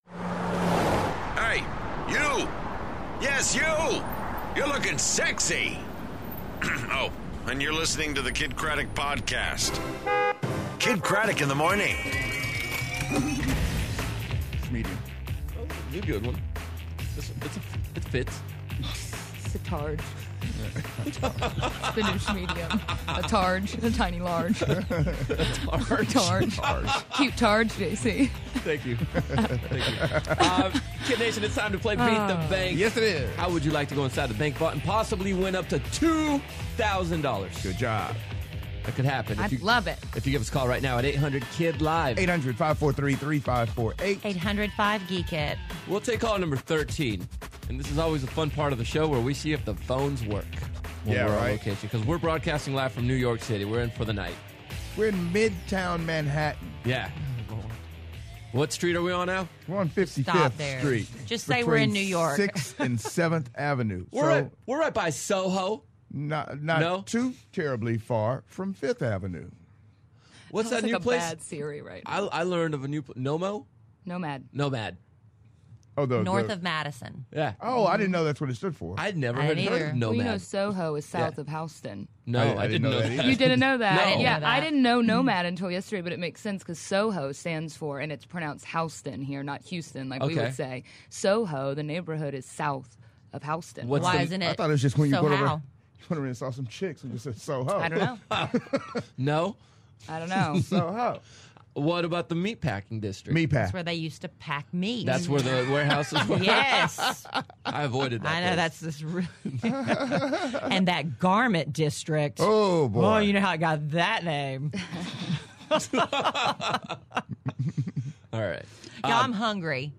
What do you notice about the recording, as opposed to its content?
Live From New York